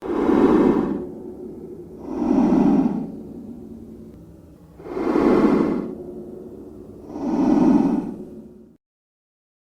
Free Nature sound effect: Underwater Ventilator.
Underwater Ventilator
Underwater Ventilator.mp3